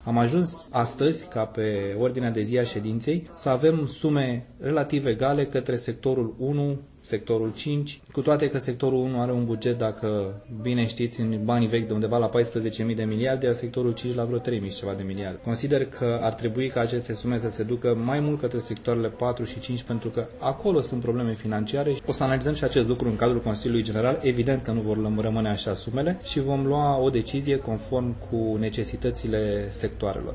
Șeful majorității din Consiliul General, Bogdan Georgescu: